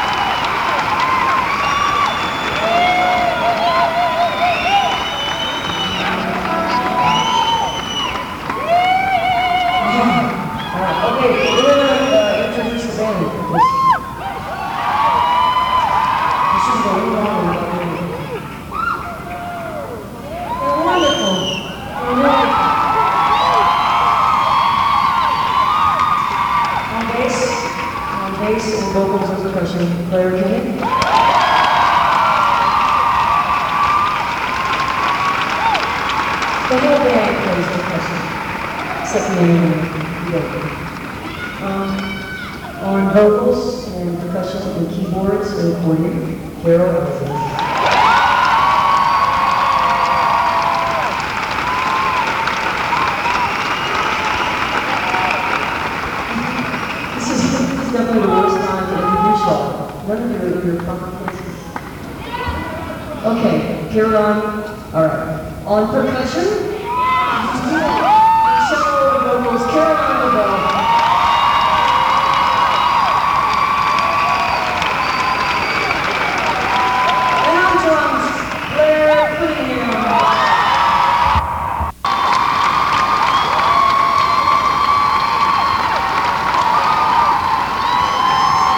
lifeblood: bootlegs: 1999-12-08: riverside theatre - milwaukee, wisconsin
14. band introductions (1:28)